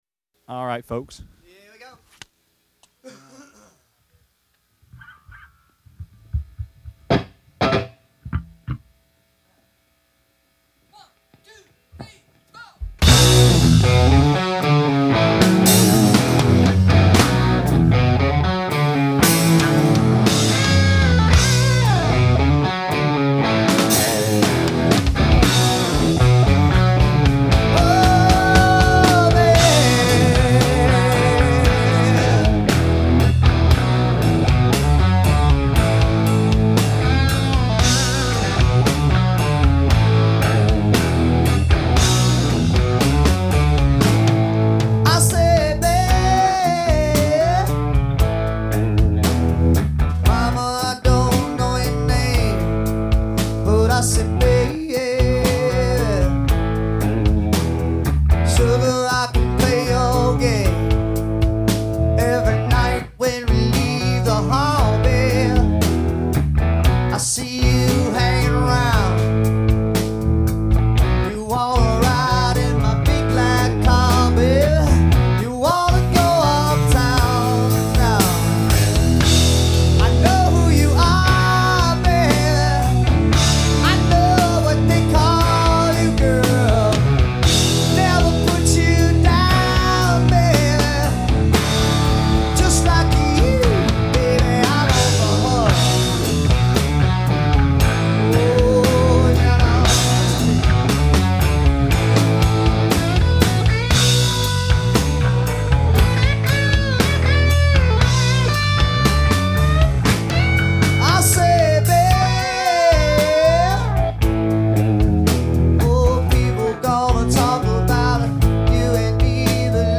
Southern Rock
totally live with no overdubs